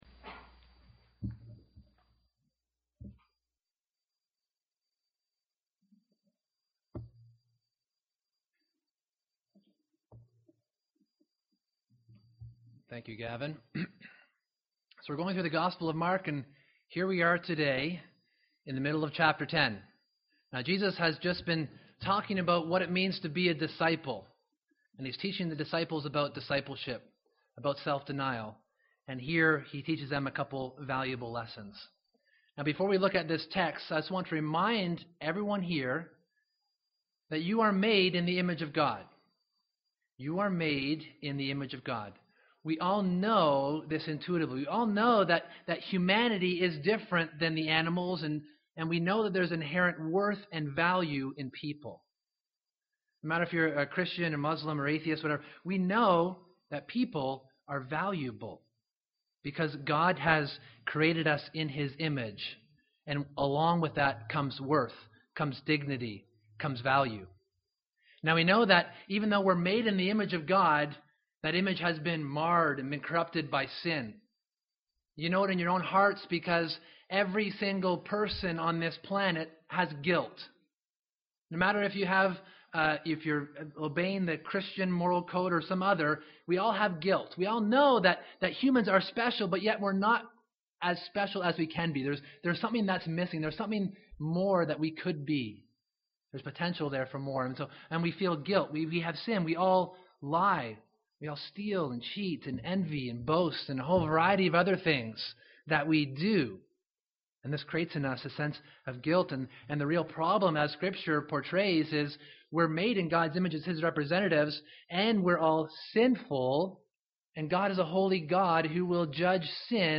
July 20, 2014 ( Sunday AM ) Bible Text